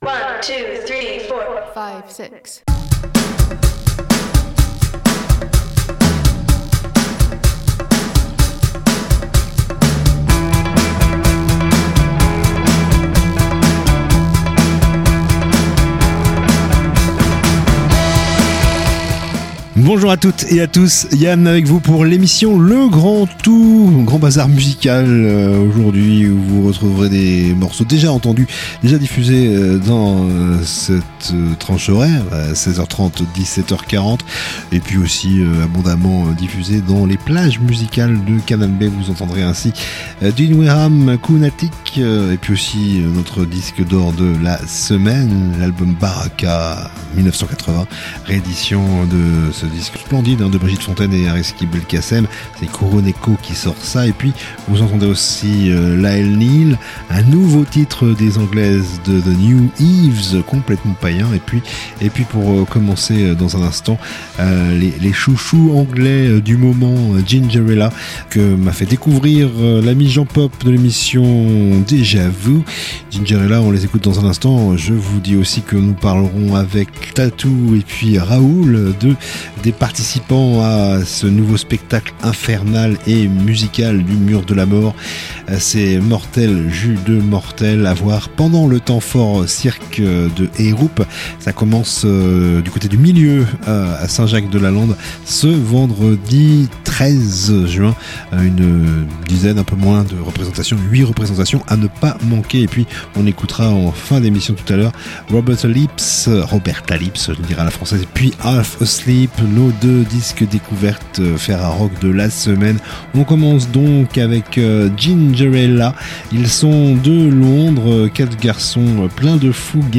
musique
Discussion